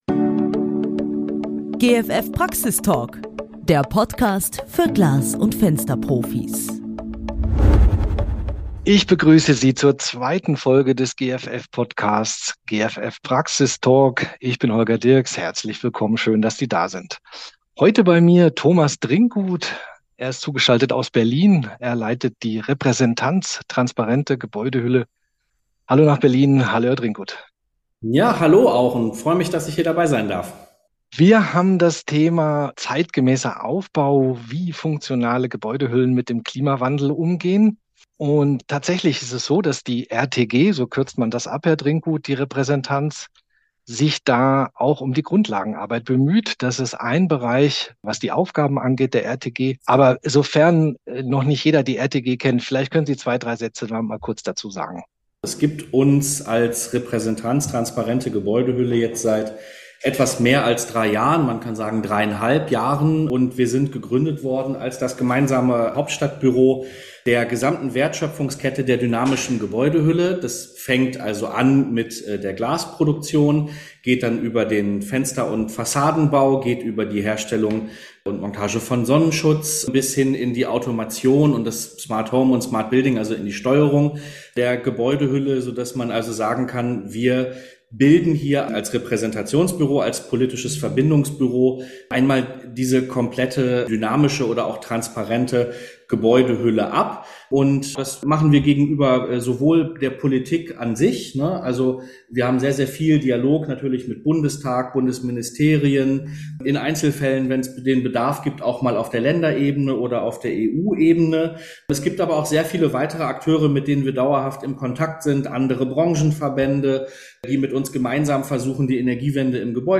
PraxisTalk